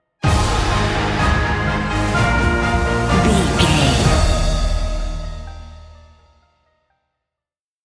Uplifting
Ionian/Major
D♯
Slow
orchestra
piano
strings